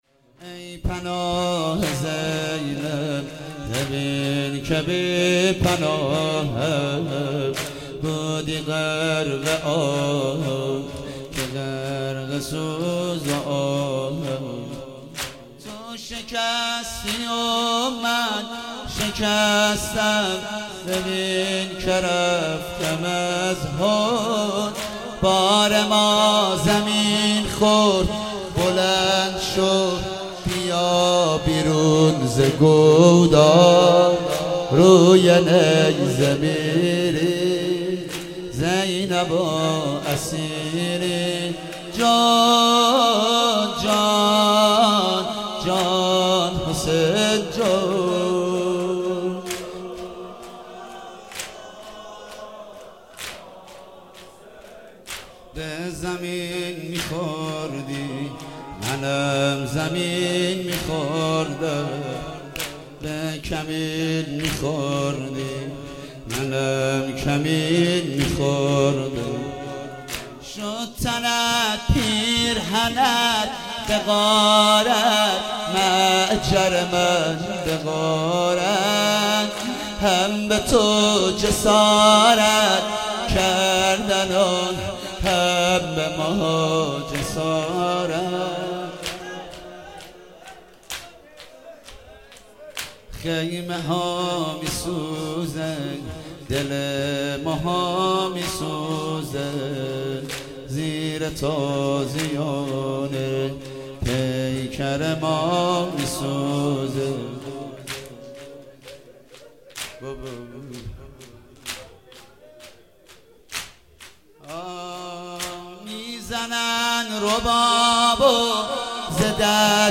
شب چهارم صفر 97 - شور - ای پناه زینب ببین که
صفر المظفر